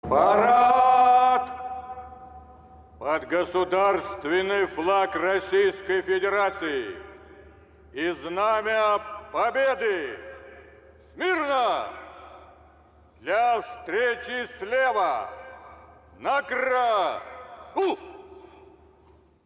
На этой странице собраны уникальные звуки Парада Победы: марши военных оркестров, рев моторов бронетехники, аплодисменты зрителей.
Парад Победы начинается с этих слов